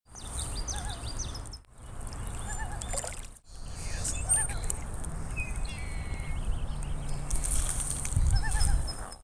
Common Loon
Gavia immer